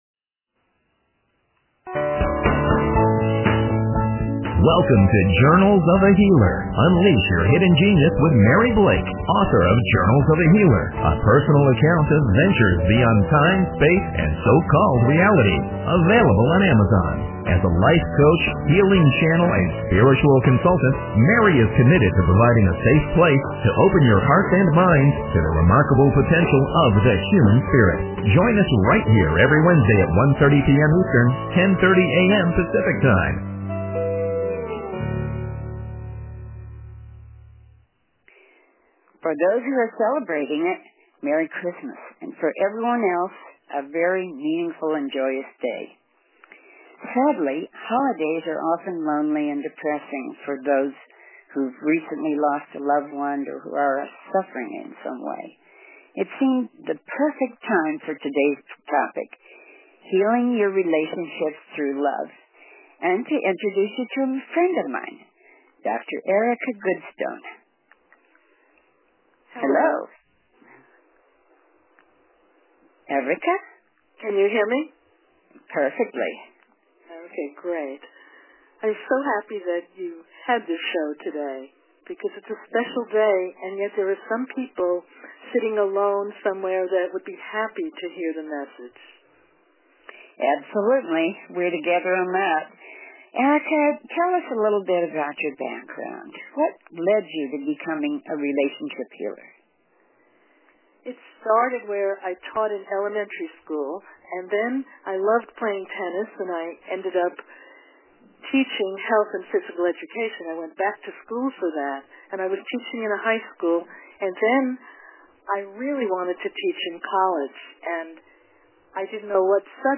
healingrelationshipsinterview.mp3